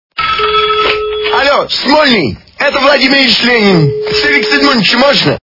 При прослушивании Звонок от Ленина - Альо, Смольный? Это Владимир Ильич Ленин качество понижено и присутствуют гудки.